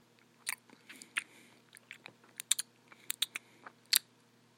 口香糖
Tag: 咀嚼 进食 UAM 拍打 4maudio17